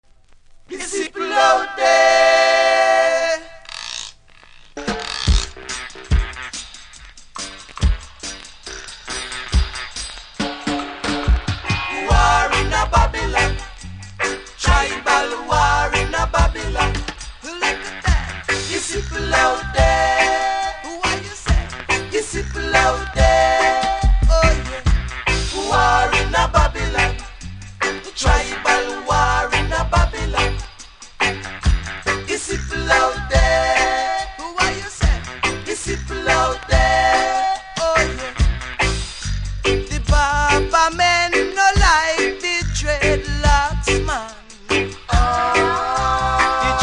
REGGAE 70'S
多少うすキズありますが音は良好なので試聴で確認下さい。